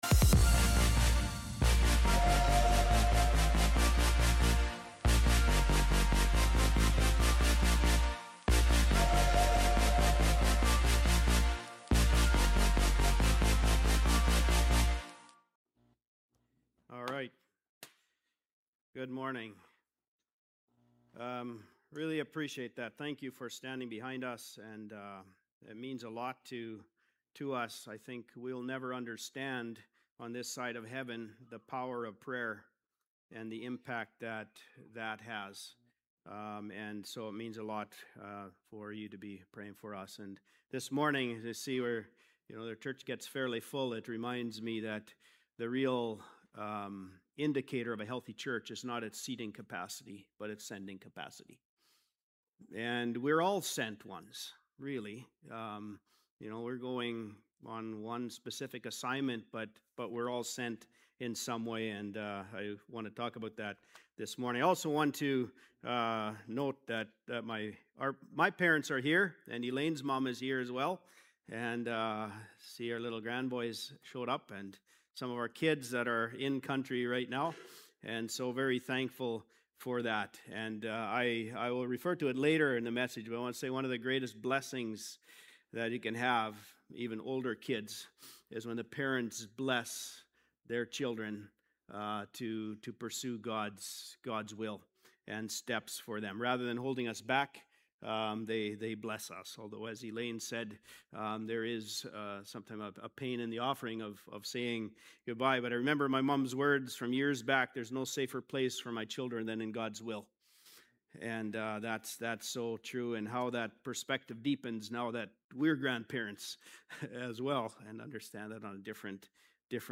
Past Message
January-11-Service.mp3